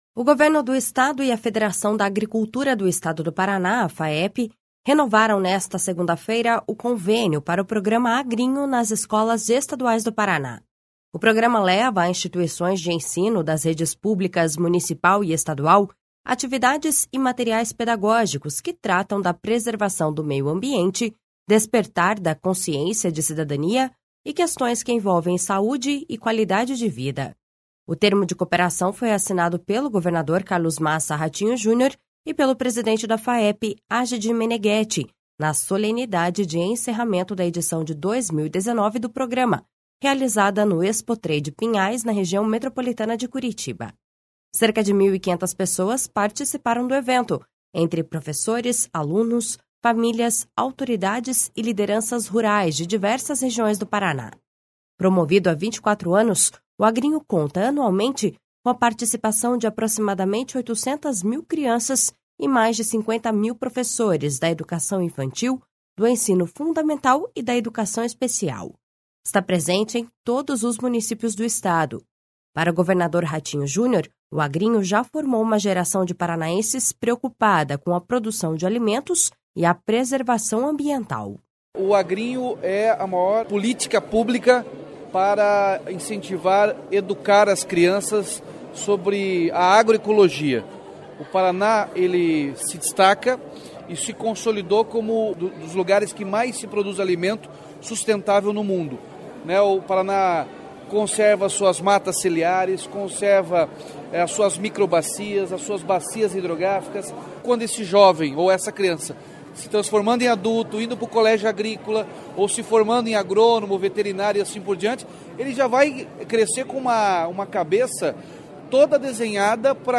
Para o governador Ratinho Junior, o Agrinho já formou uma geração de paranaenses preocupada com a produção de alimentos e a preservação ambiental.// SONORA RATINHO JUNIOR.//
A continuidade no trabalho do campo também é uma consequência do programa, como afirmou o secretário estadual da Agricultura, Norberto Ortigara.// SONORA NORBERTO ORTIGARA.//